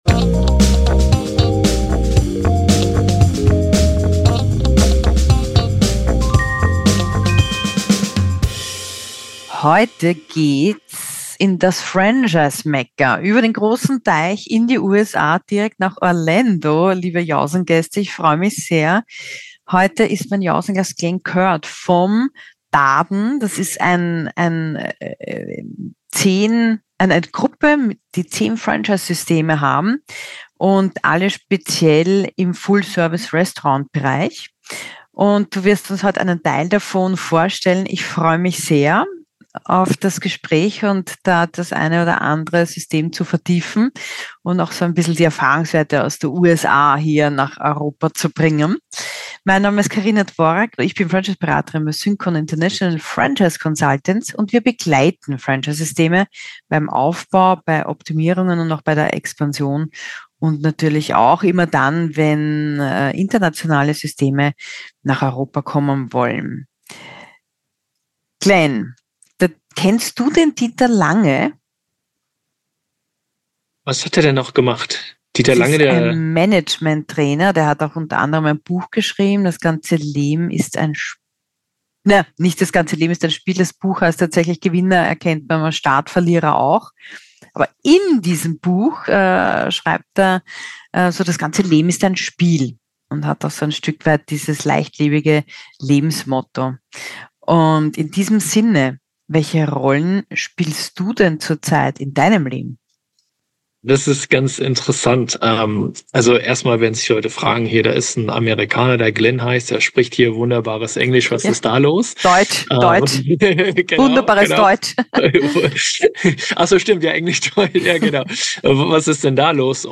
Ein Gespräch über Restaurantkonzepte, Fine Dining, Fullservice Restaurants, Marktanpassungen und Qualitätskontrolle